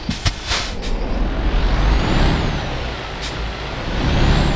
Index of /server/sound/vehicles/lwcars/truck_2014actros